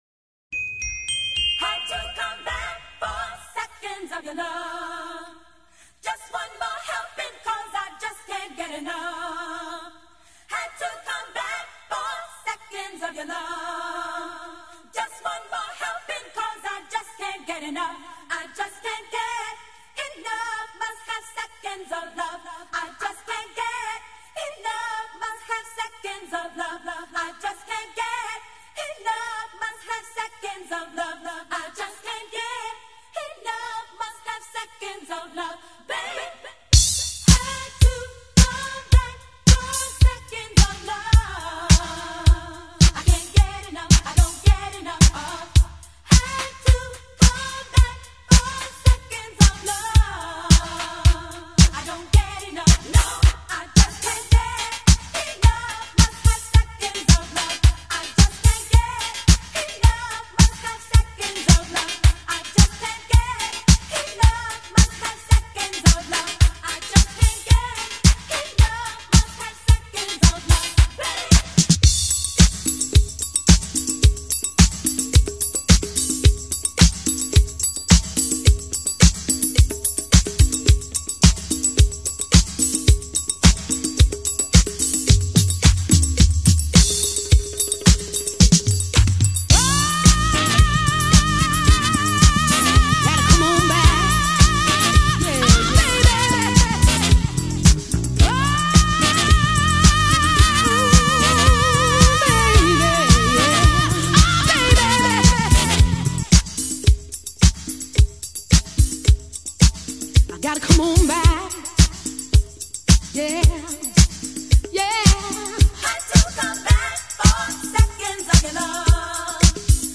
original extended 12″ mix